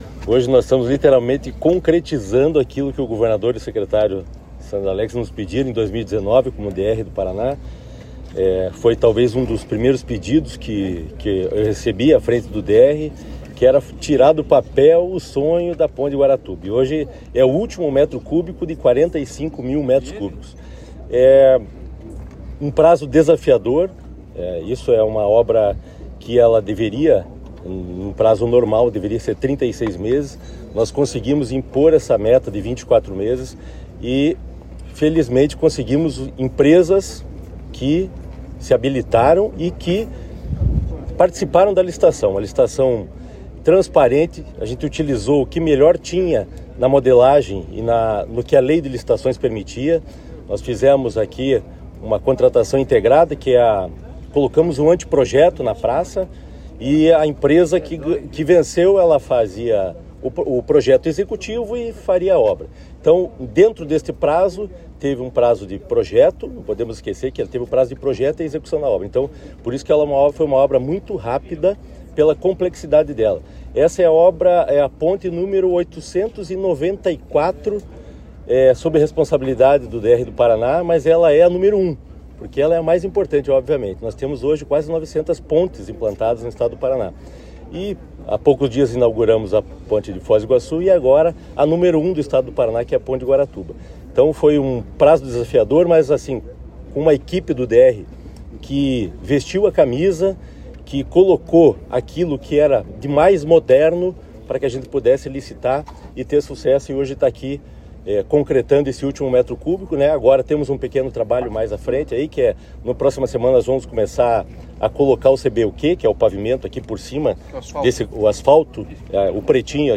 Sonora do diretor-geral do DER, Fernando Furiatti, sobre sobre o "beijo" da Ponte de Guaratuba